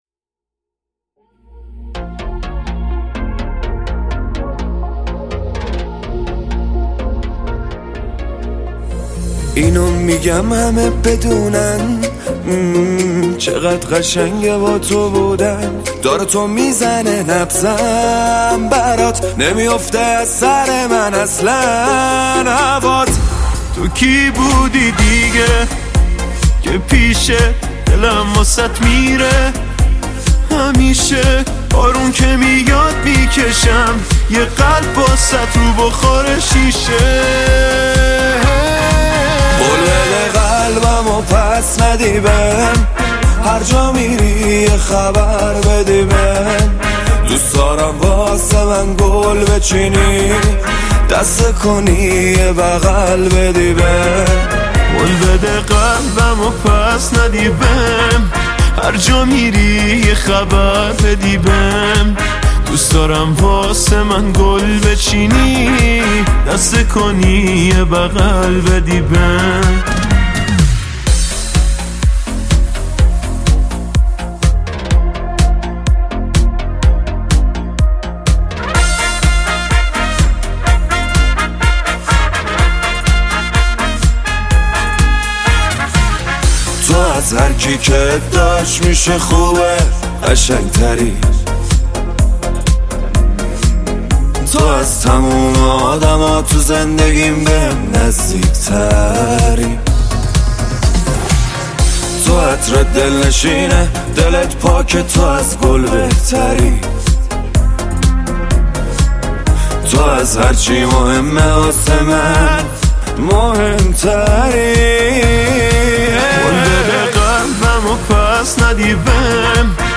دانلود آهنگ غمگین جدید